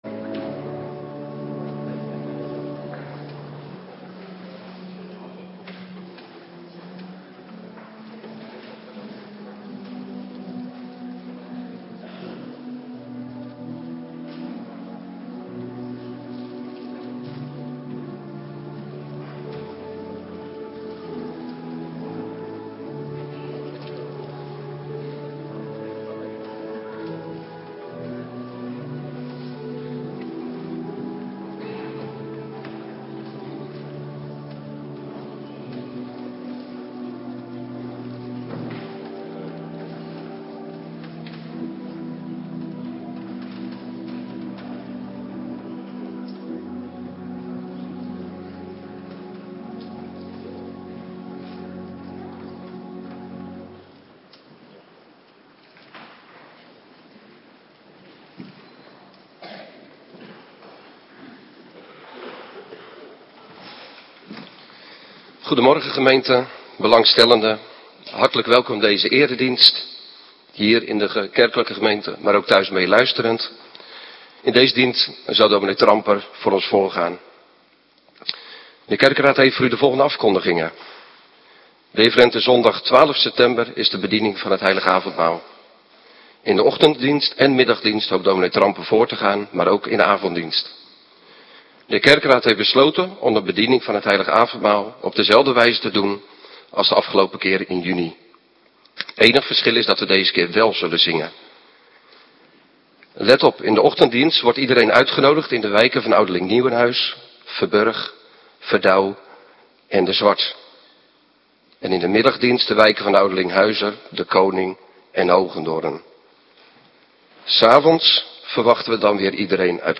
Morgendienst voorbereiding Heilig Avondmaal
Locatie: Hervormde Gemeente Waarder